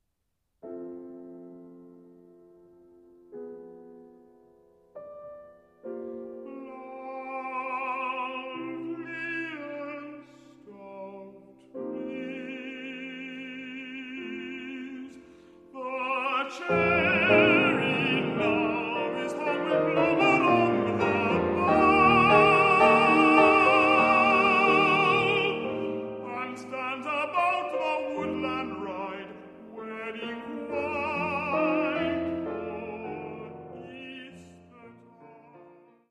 tenor
piano